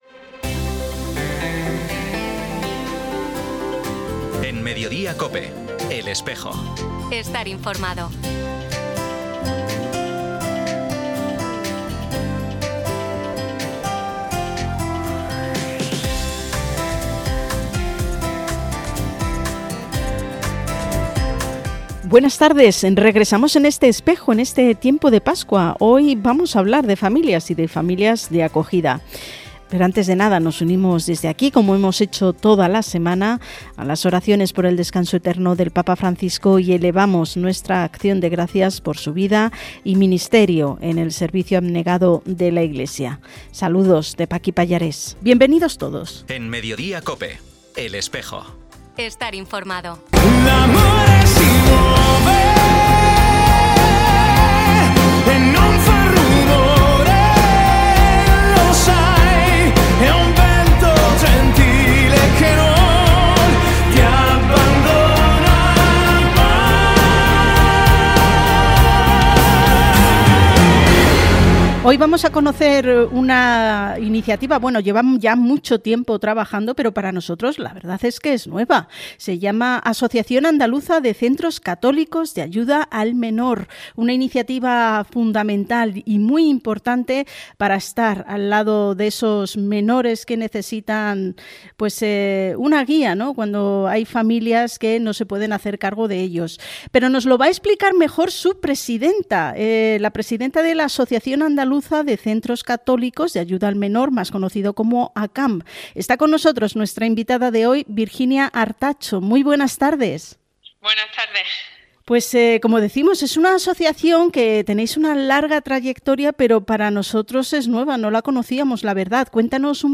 Programa emitido en COPE Granada y COPE Motril el 25 de abril de 2025.